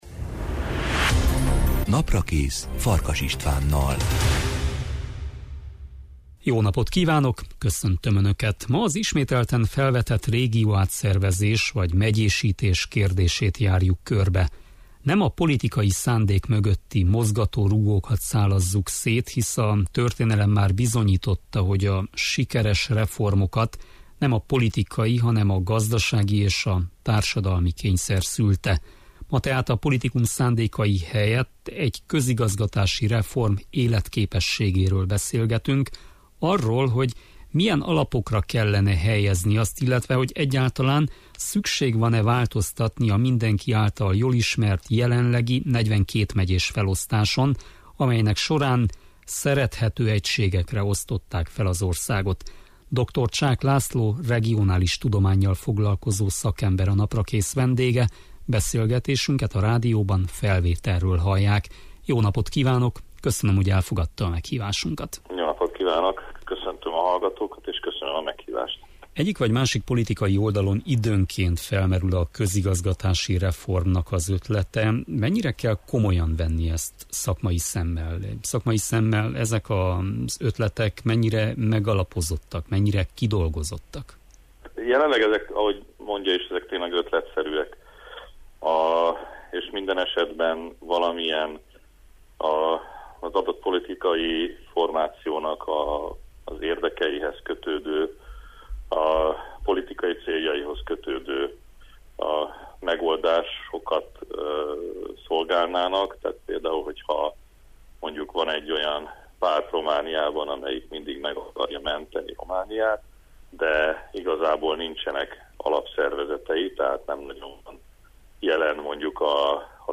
regionális tudománnyal foglalkozó szakember, a Naprakész vendége.